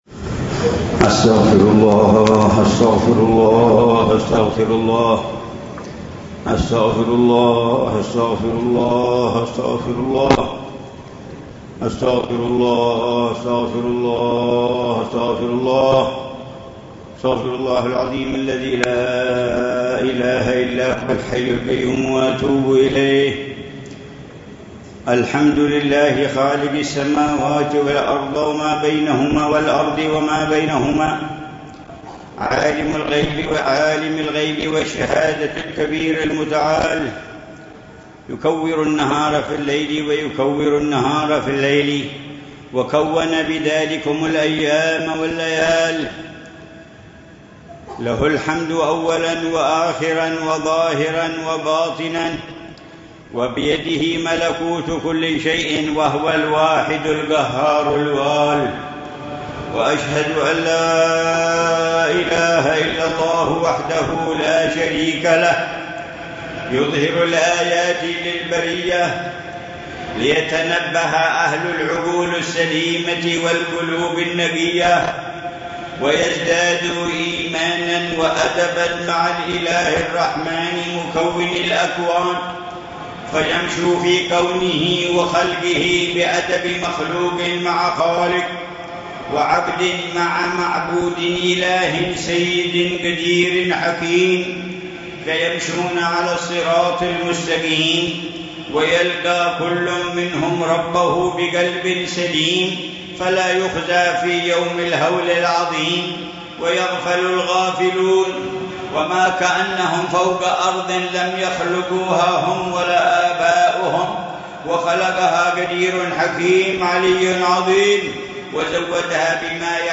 خطبة خسوف القمر للعلامة الحبيب عمر بن محمد بن حفيظ، في مسجد المحضار في مدينة المكلا، ليلة الأحد 14 ربيع الثاني 1445هـ